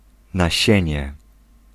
Ääntäminen
IPA : /ˈsiːmən/